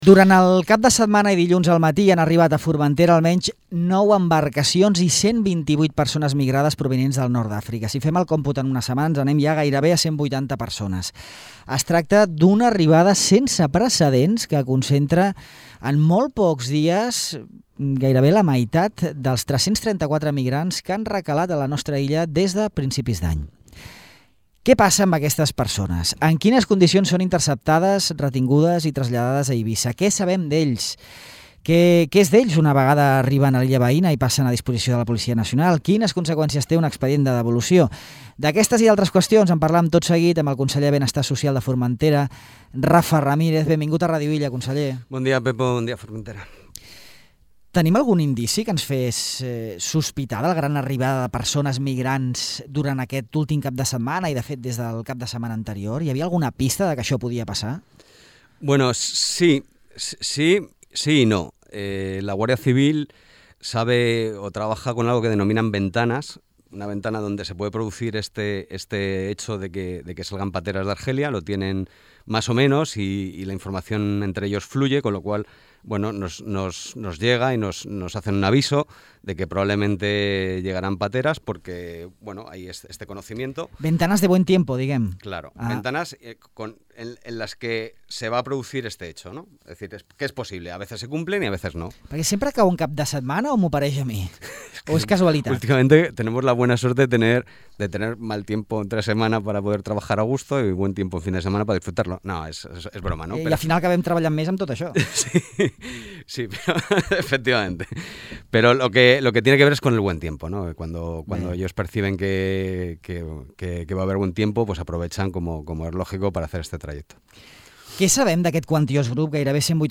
El conseller de Benestar Social, Rafa Ramírez, explica quins processos viuen aquestes persones des que recalen a la nostra illa i fins que són posades en llibertat, una vegada se’ls ha tramitat l’expedient de devolució. Ramírez subratlla que el perfil dels nouvinguts s’està diversificant les darreres setmanes: ara no només arriben algerians sinó que també s’han detectat ciutadans de Líbia i Mali.